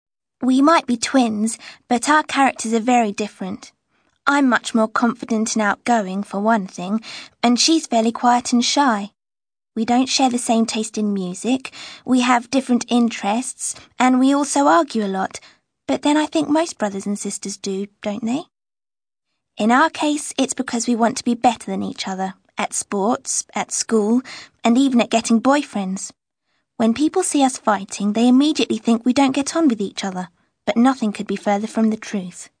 You will hear five people talking about their sisters.